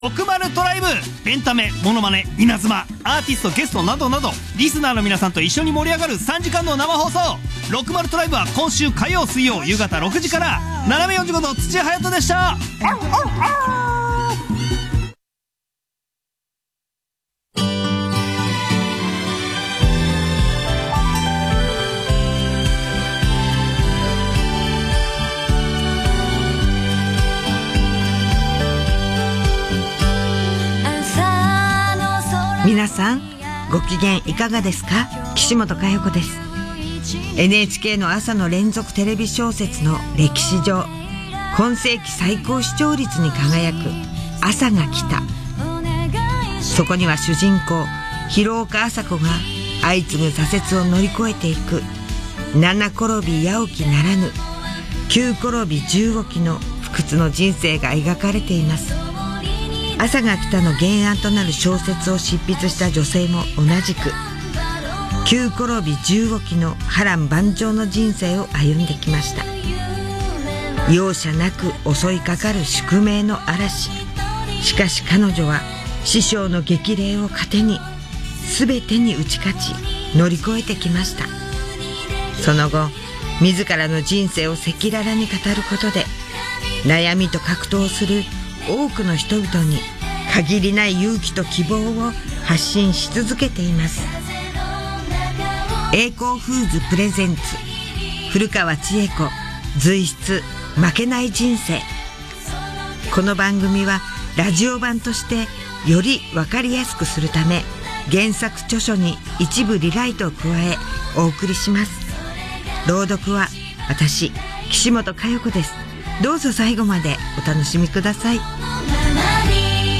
roudoku1007.mp3